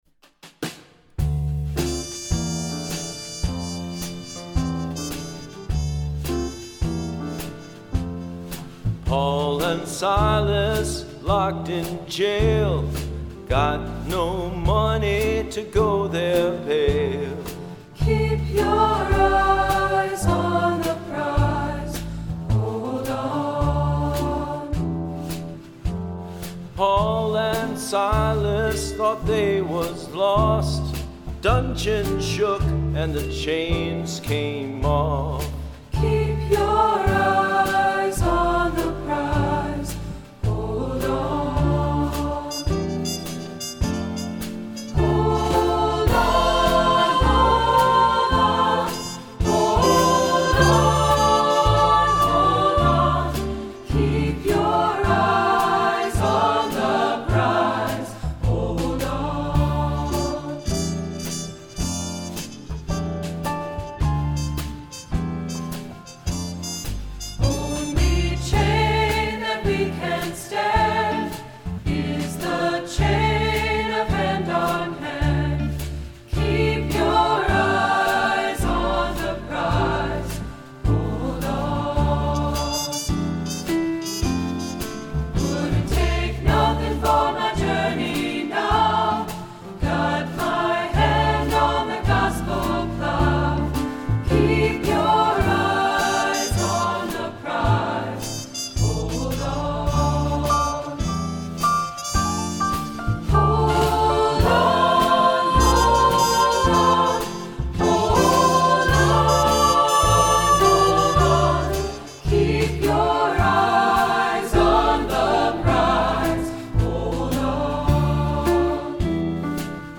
Accompaniment:      Keyboard, Trumpet in B-flat;Trumpet in C
Music Category:      Christian